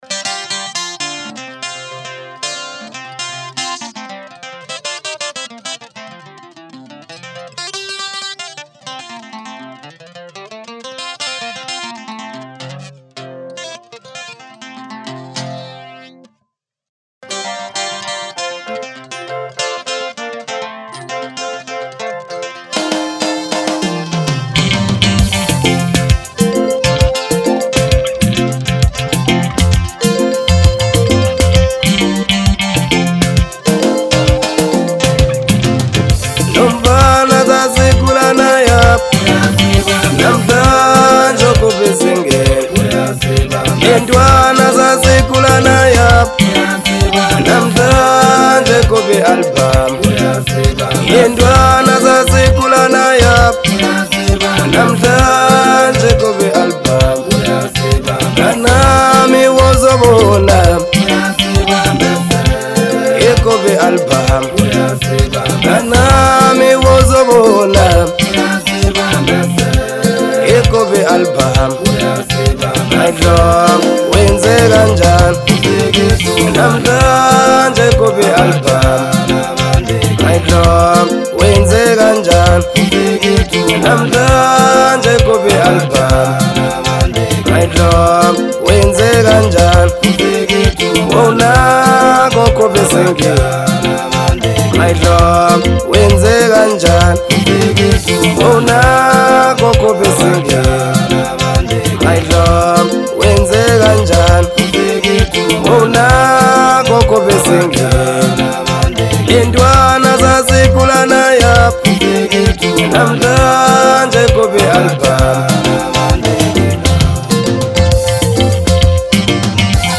Genre : Maskandi